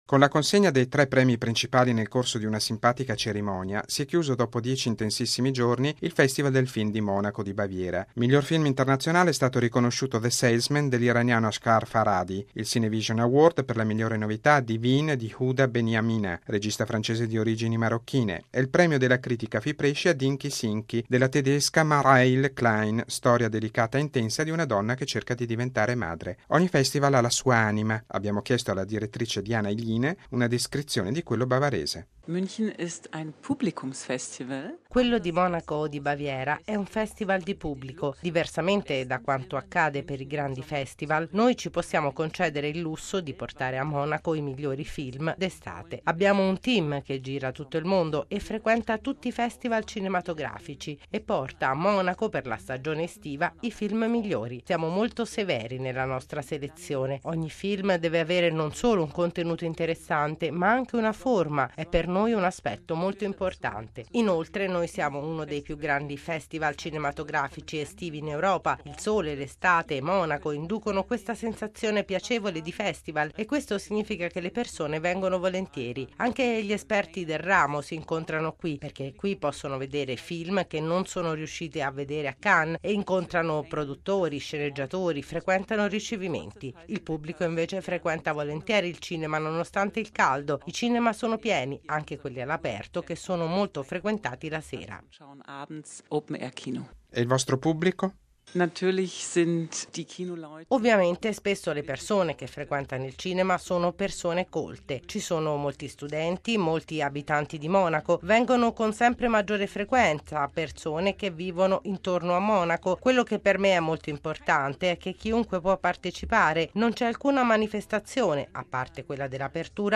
Si è chiuso da pochi giorni il Festival del Film di Monaco: un evento che investe tutta la bella città della Baviera con decine di proiezioni e un pubblico soprattutto di giovani, attenti e coinvolti. Il servizio